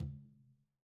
Tumba-HitN_v2_rr1_Sum.wav